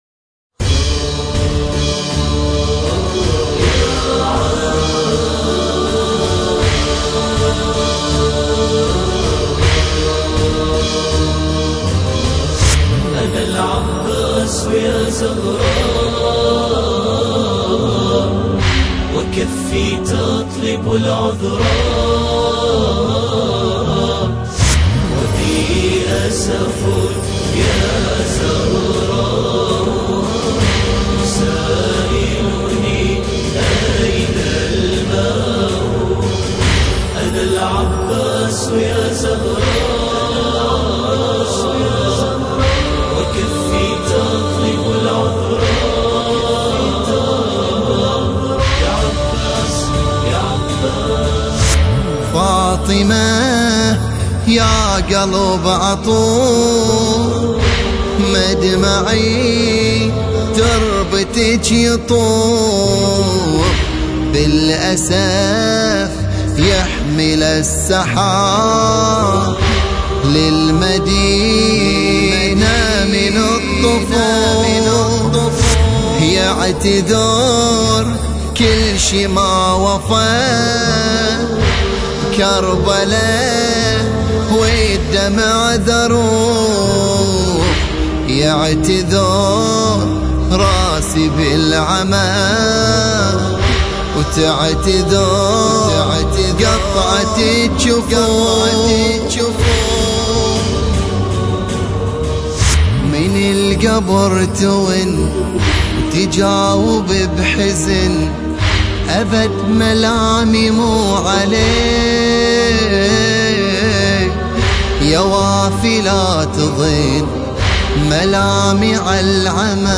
مراثي